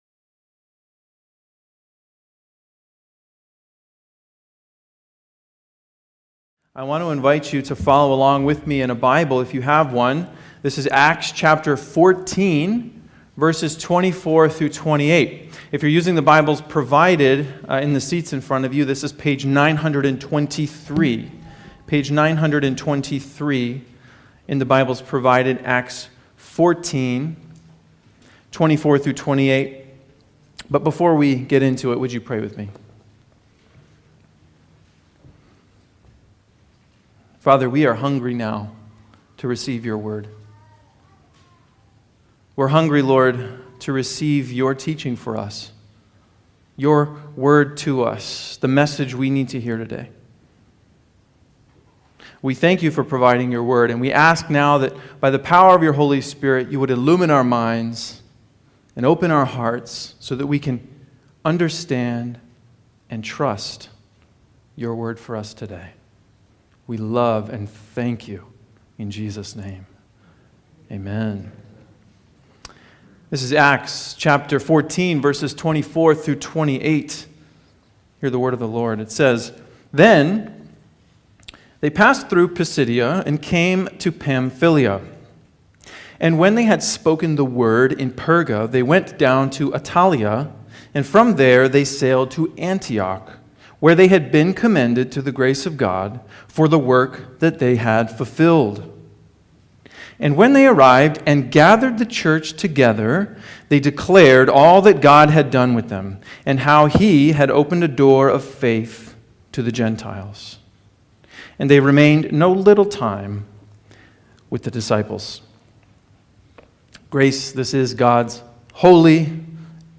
Grace Sermons | Grace Evangelical Free Church